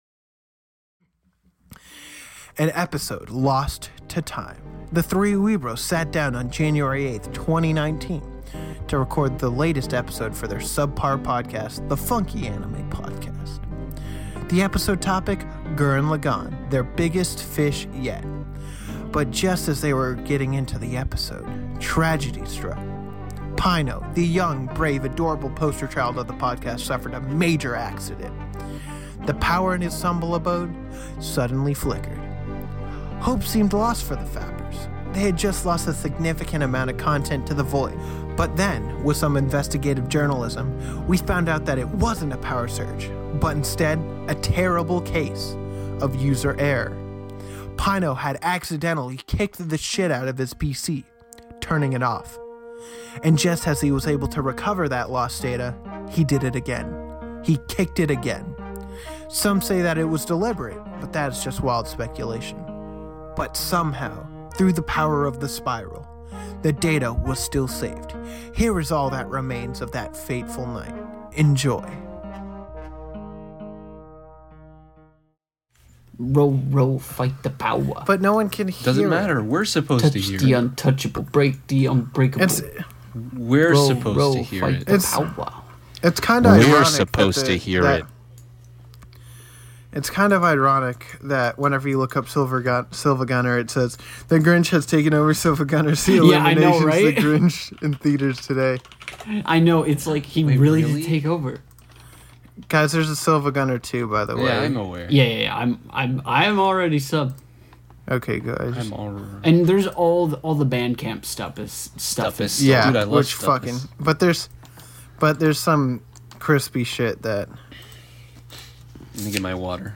This particular episode of FAP actually resulted due to some technical failures when recording. We didn't have time to record the full thing, so we are putting this up now as a kind of behind-the-scenes before recording, and we will be putting up a full Gurren Lagann episode next week!